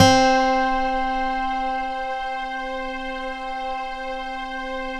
Here is a wave file with embedded loop tags: